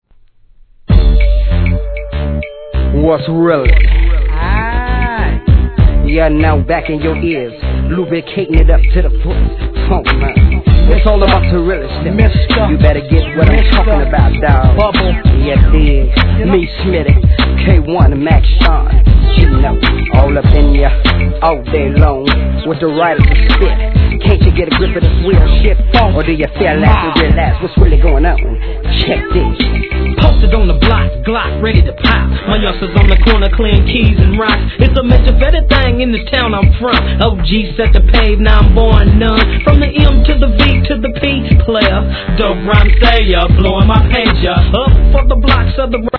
G-RAP/WEST COAST/SOUTH
ヒップホップ定番の大ネタA-1, そして心癒す夏メロソングA-2,メローFUNKのB-2とご馳走様EP盤!!